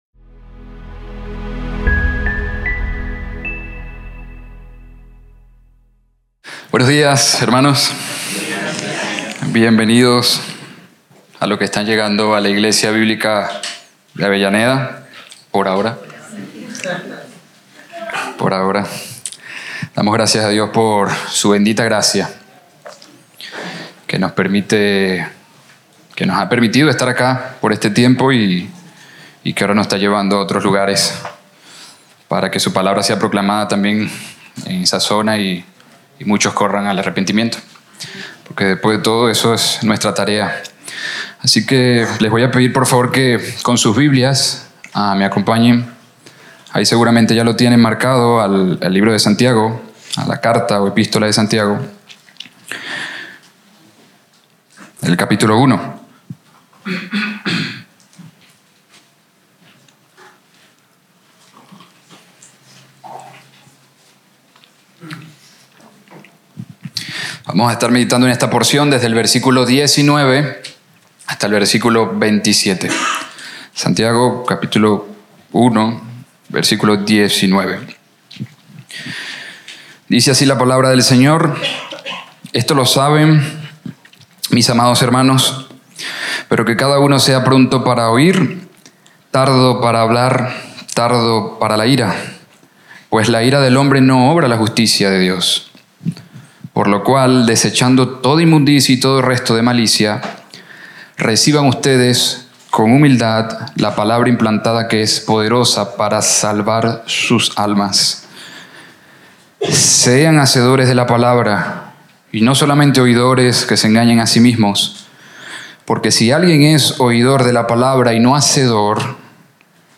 Sermón